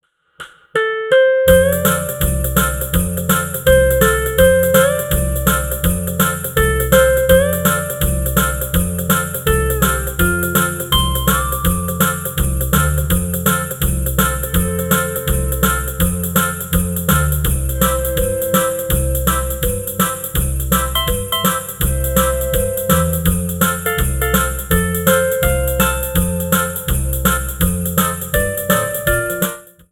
This is an instrumental backing track cover.
• Key – F
• Without Backing Vocals
• With Fade